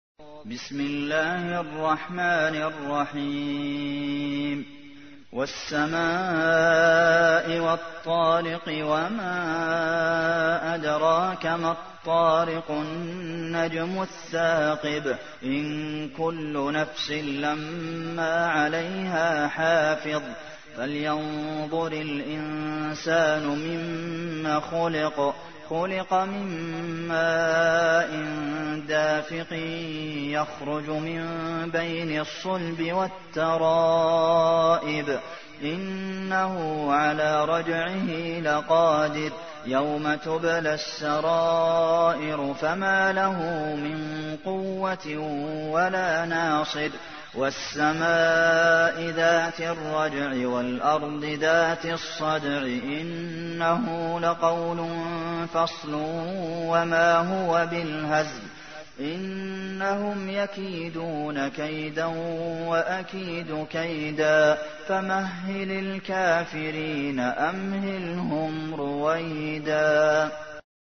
تحميل : 86. سورة الطارق / القارئ عبد المحسن قاسم / القرآن الكريم / موقع يا حسين